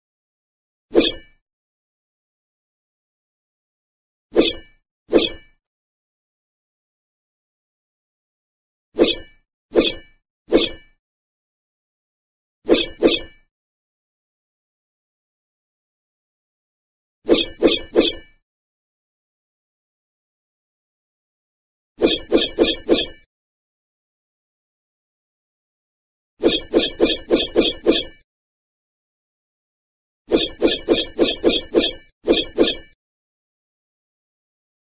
Suara Tebasan Pedang Ninja
Kategori: Suara senjata tempur
Keterangan: Dengarkan suara tebasan pedang ninja yang seru, unduh dan edit video untuk membuat nada dering atau notifikasi yang unik.
suara-tebasan-pedang-ninja-id-www_tiengdong_com.mp3